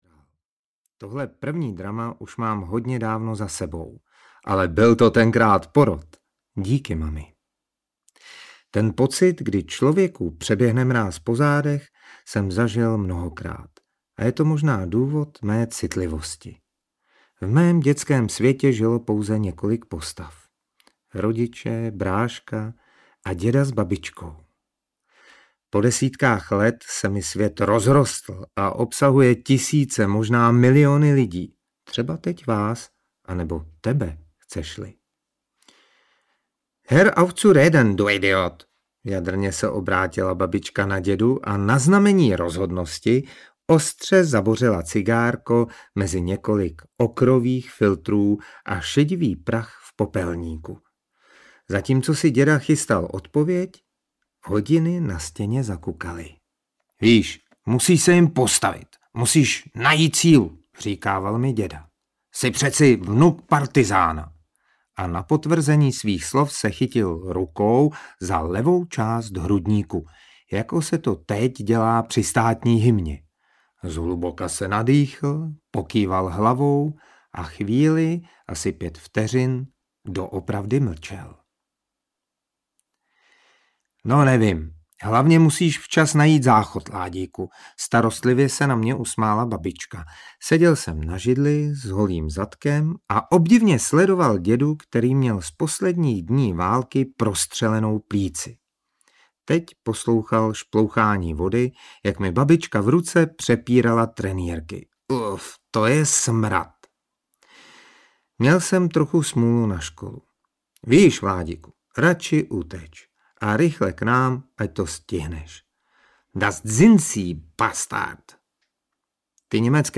Řeka zázraků audiokniha
Ukázka z knihy